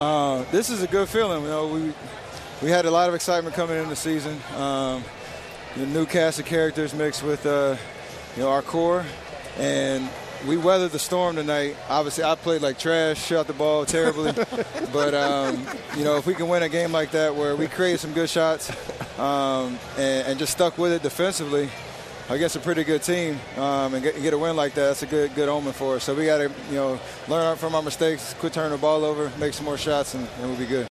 Also, Curry talked about his performance in a recent post game interview.